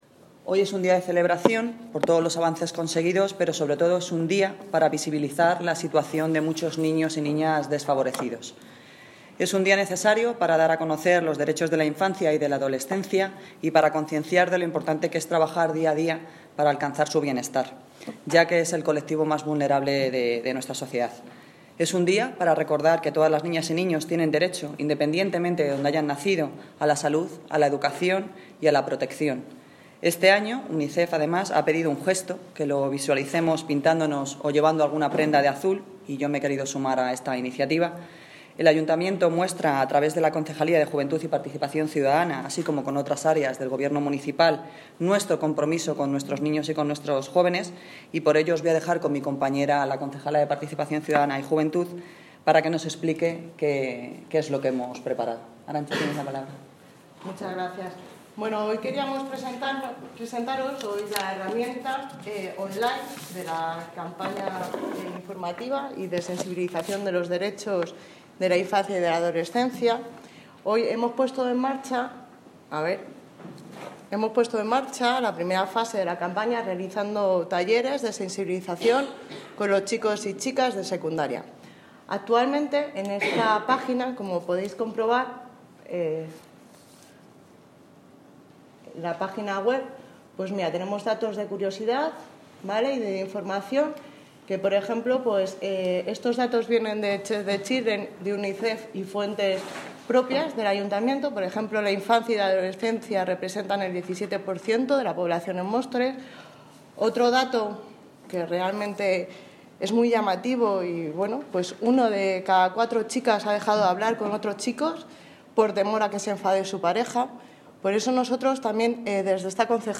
Noelia Posse(Alcadesa de Móstoles) sobre Día Universal del Niño